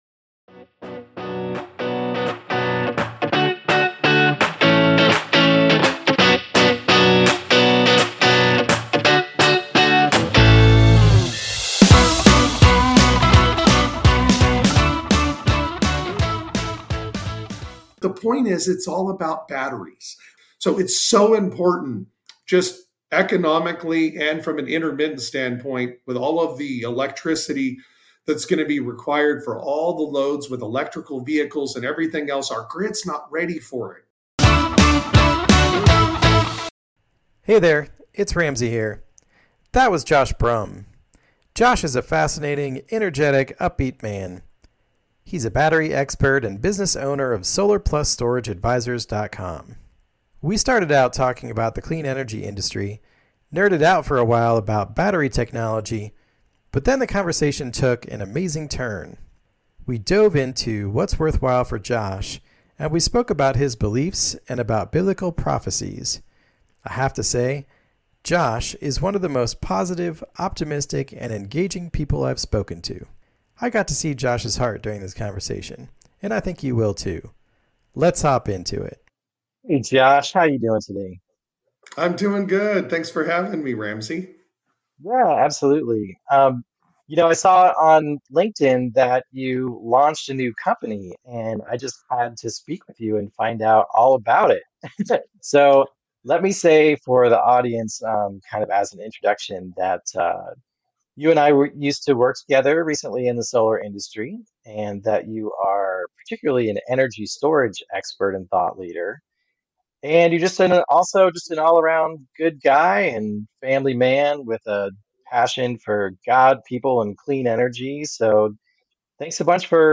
Listen in to this electrifying conversation!